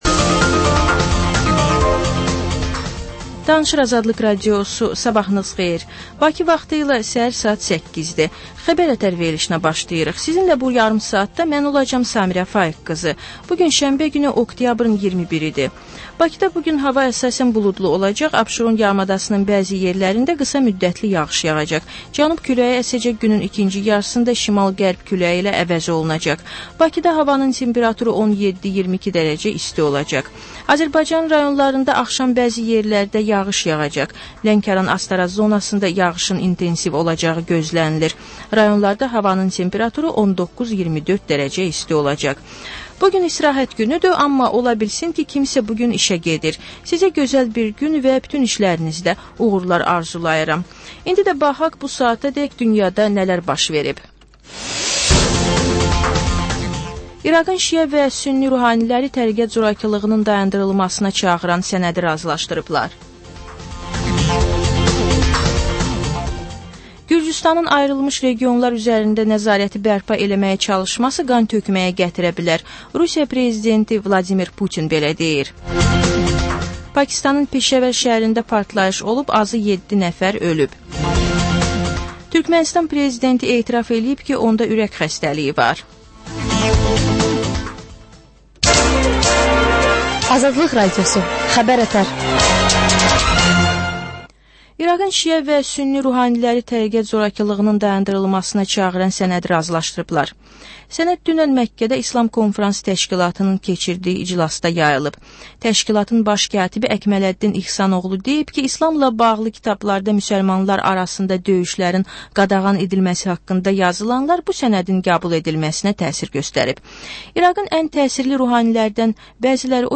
S əhər-səhər, Xəbər-ətər: xəbərlər, reportajlar, müsahibələrVə: Canlı efirdə dəyirmi masa söhbətinin təkrarı.